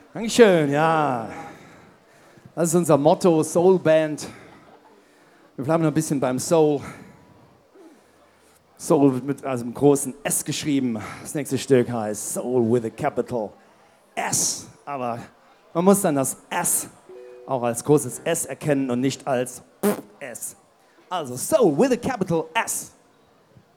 08 - Ansage.mp3